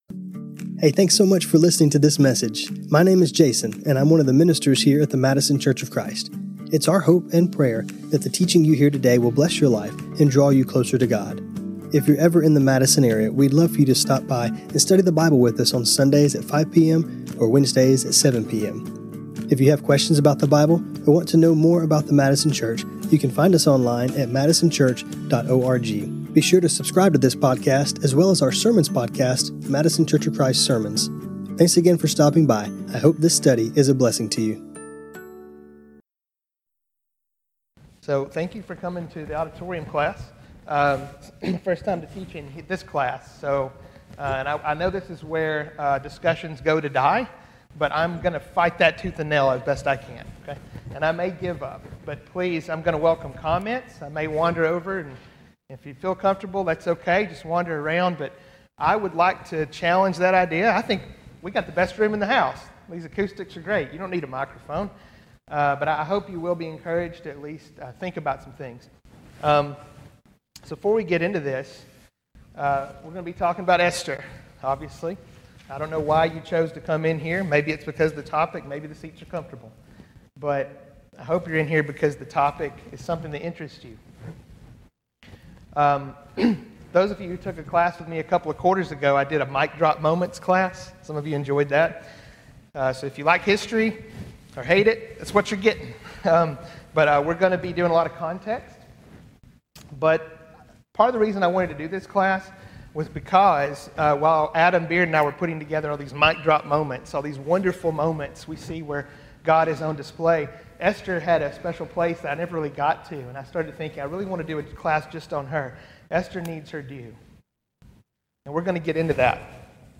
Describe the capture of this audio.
This class was recorded on Feb 4, 2026.